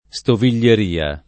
[ S tovil’l’er & a ]